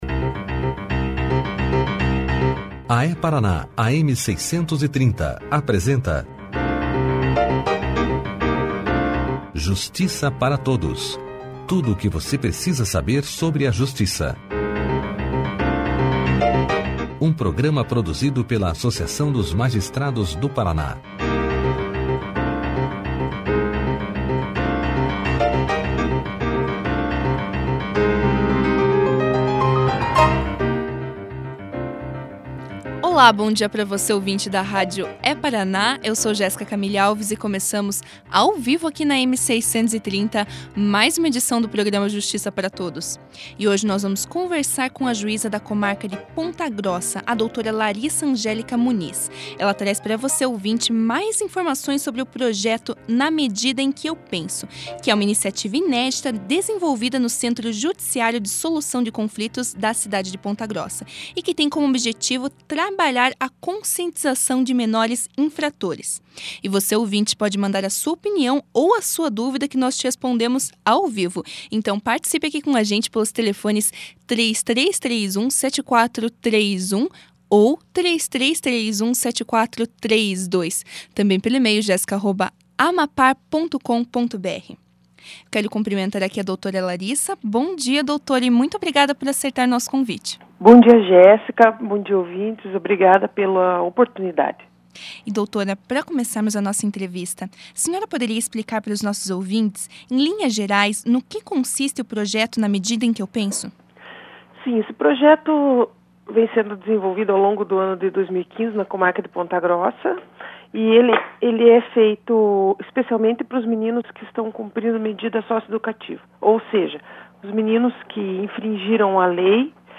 Clique aqui e ouça a entrevista da juíza Laryssa Angélica Muniz sobre o programa Na Medida Em Que Eu Penso na íntegra.